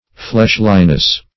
fleshliness - definition of fleshliness - synonyms, pronunciation, spelling from Free Dictionary
Search Result for " fleshliness" : The Collaborative International Dictionary of English v.0.48: Fleshliness \Flesh"li*ness\, n. The state of being fleshly; carnal passions and appetites.
fleshliness.mp3